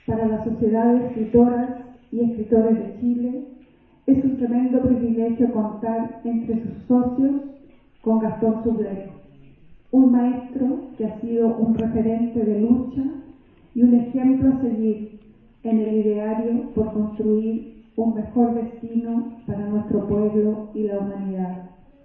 En una ceremonia memorable, la Universidad de Playa Ancha entregó el grado de Doctor Honoris Causa a Gastón Soublette Asmussen.